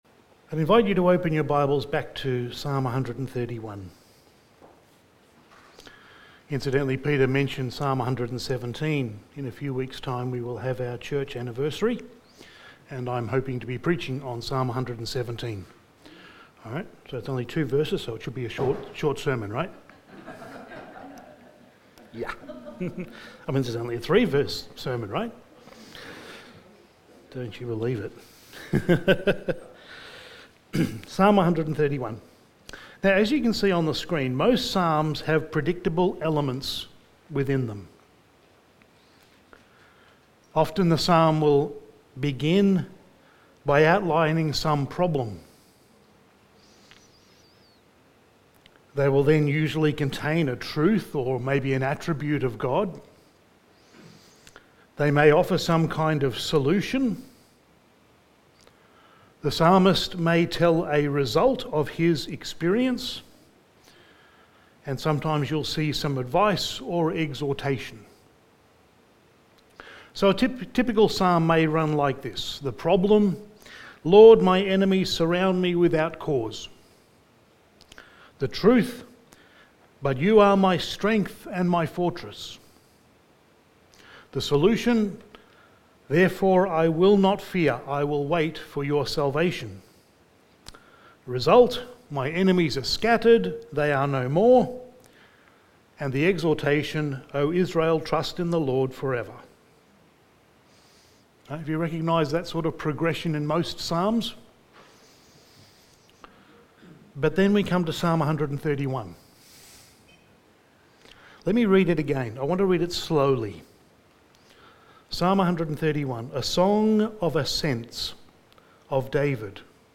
Topical Sermon Passage: Psalm 131:1-3 Service Type: Sunday Evening « Exhortations for a Healthy Church